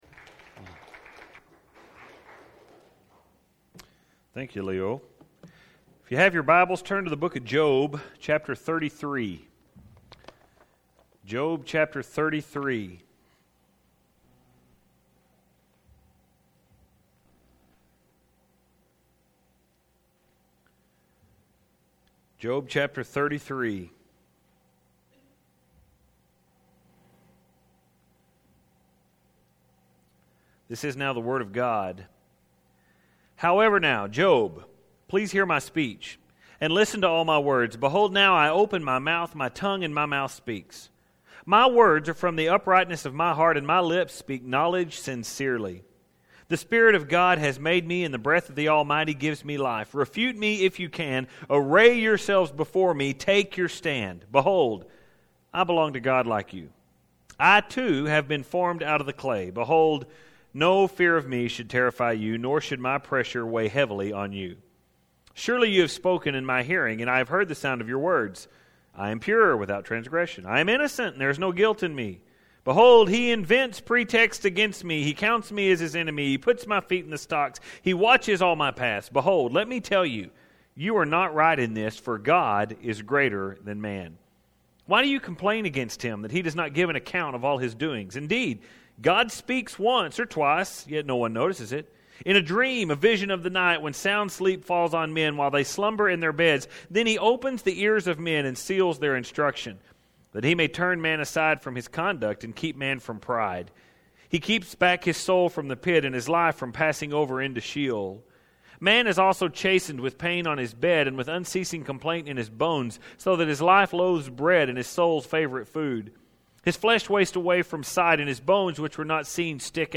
Well, as you know we are now examining THE FINAL SERMON of the book of Job.